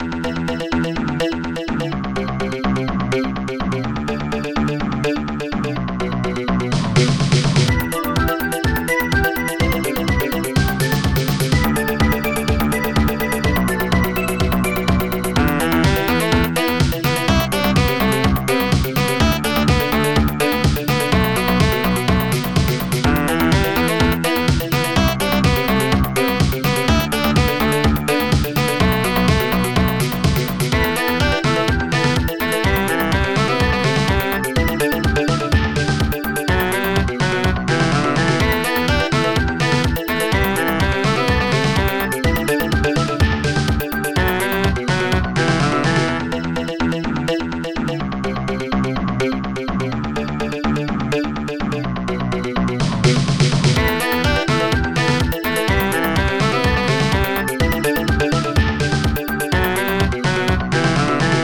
Protracker Module
ST-76:dm.snaredrum
ST-01:HiHat2 ST-01:Organ